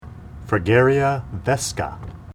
Pronunciation Cal Photos images Google images